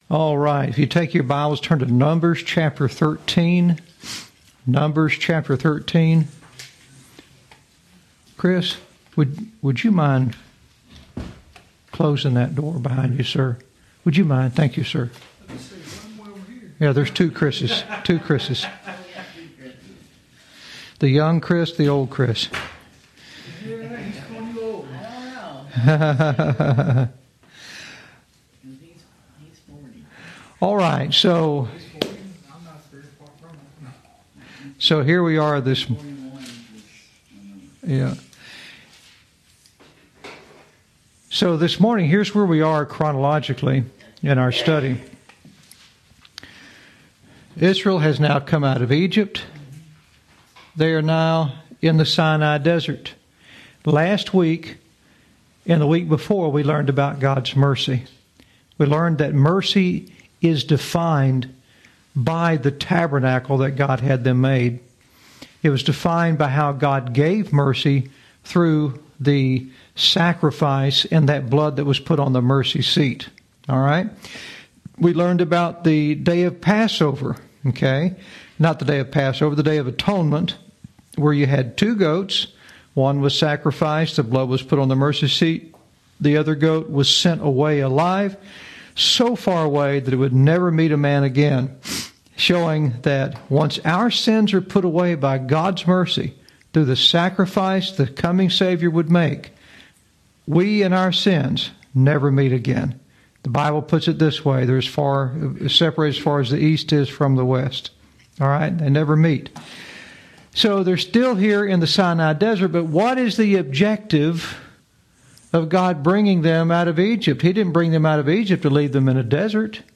Lesson 26